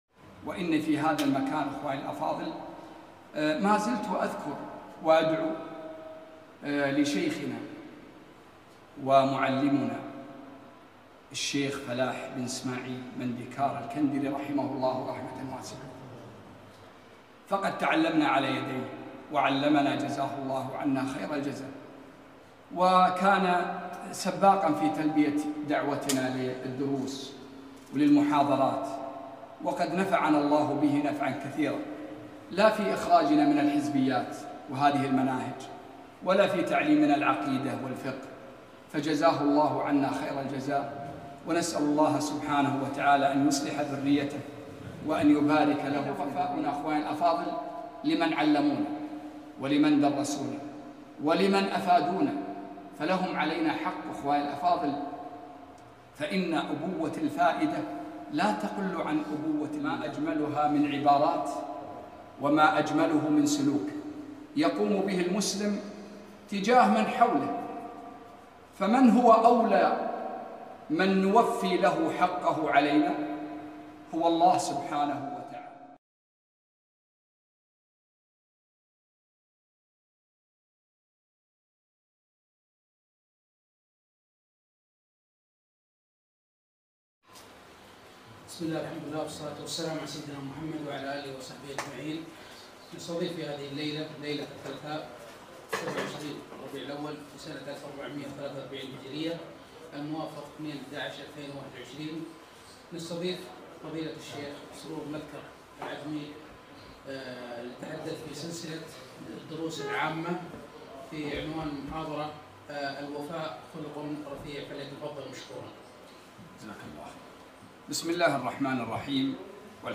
محاضرة - الوفاء خلق رفيع - دروس الكويت